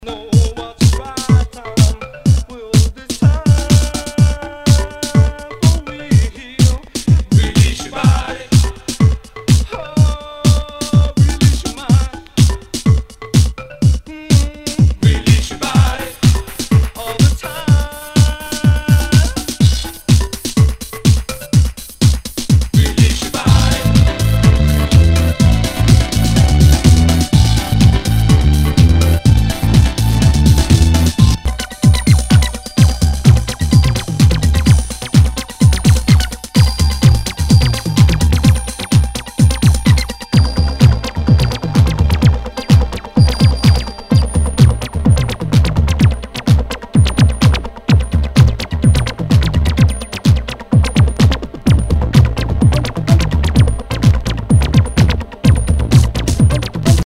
HOUSE/TECHNO/ELECTRO
ナイス！テクノ・クラシック！